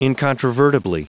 Prononciation du mot incontrovertibly en anglais (fichier audio)
Prononciation du mot : incontrovertibly